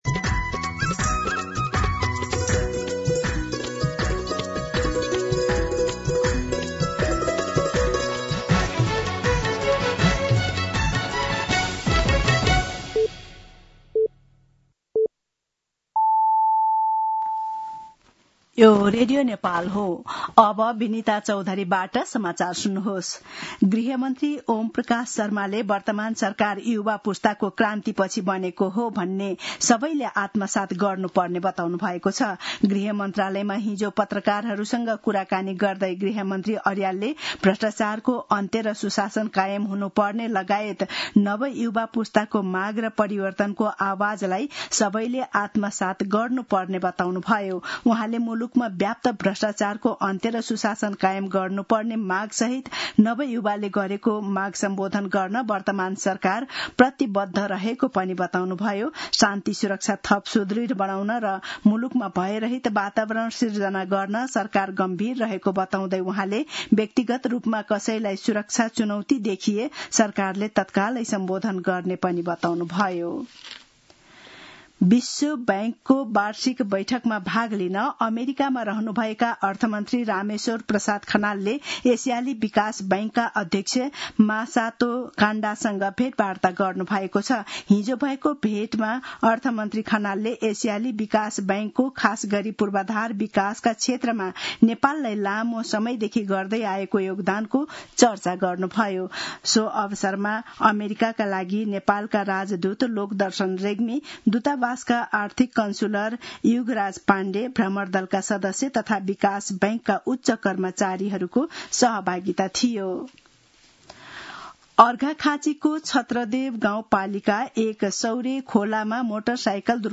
मध्यान्ह १२ बजेको नेपाली समाचार : ३० असोज , २०८२
12-pm-Nepali-News-5.mp3